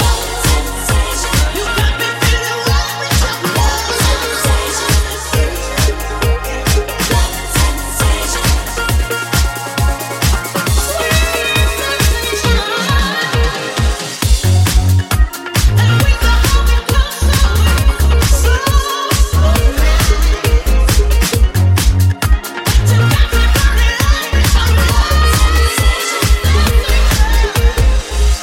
deep house
Genere: house, deep house, remix